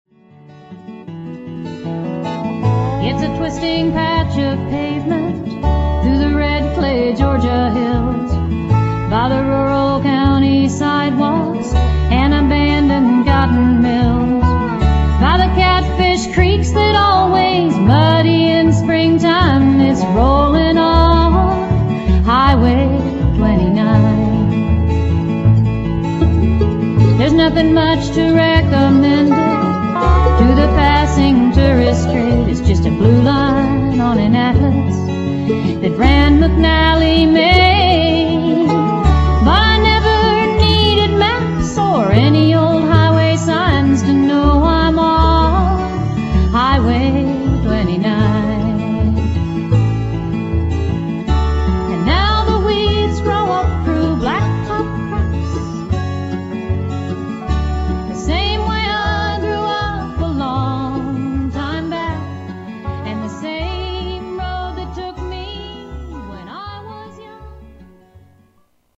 mandolin, guitar, harmony vocals
Dobro, harmony vocals
upright bass
banjo
fiddle, viola
guitar, harmonica